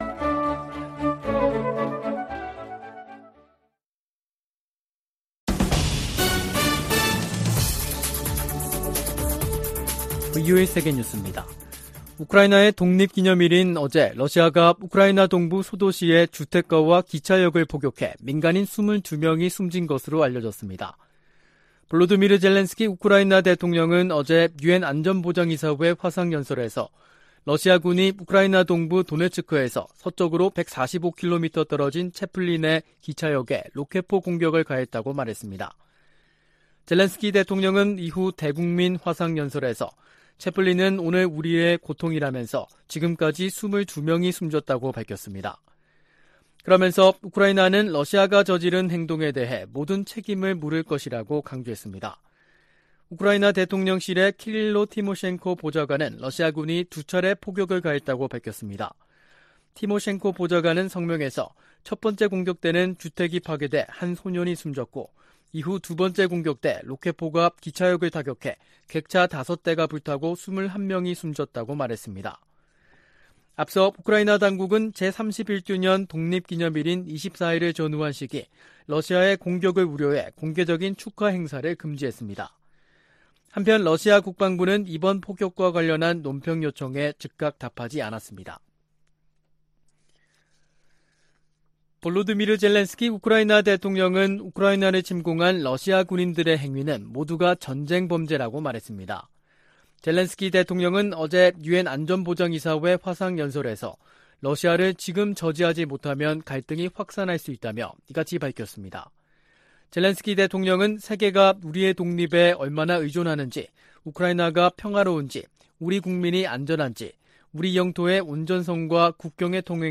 VOA 한국어 간판 뉴스 프로그램 '뉴스 투데이', 2022년 8월 25일 2부 방송입니다. 일각에서 거론되는 ‘미북 관계 정상화’ 방안과 관련해 미국 정부는 ‘동맹과의 긴밀한 협력’이 중요하다고 밝혔습니다. 주한미군의 사드는 한국을 보호하기 위한 방어체계라고 미 국방부가 강조했습니다. 약 두 달 앞으로 다가온 미국 중간선거에서, 한반도 문제에 적극 개입해 온 주요 의원들이 재선될 것으로 관측됩니다.